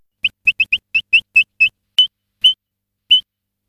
Pluvier guignard
Charadrius morinellus
pluvier.mp3